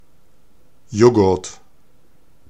Ääntäminen
IPA : /ˈjoʊɡɚt/